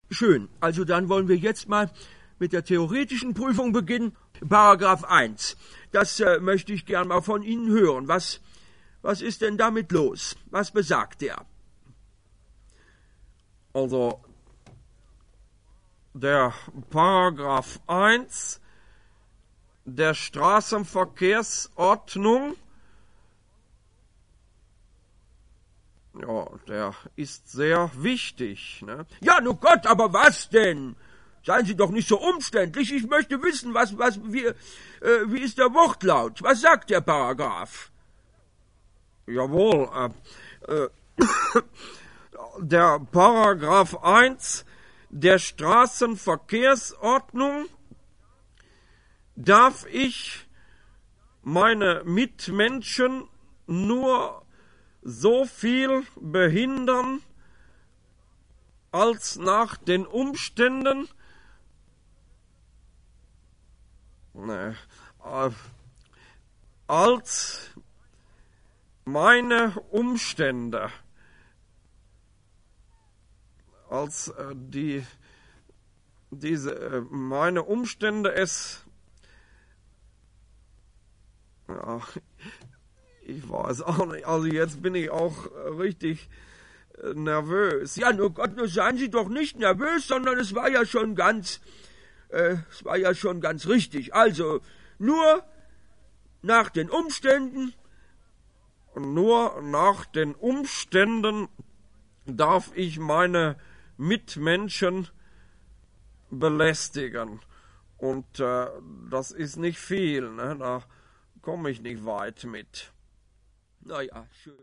Bis zu sechszig Jahre alte Aufzeichnungen vom Radio.
Zunächsst mit einem Spulentonbandgerät. Dannn mit dem Kasssettenrekorder.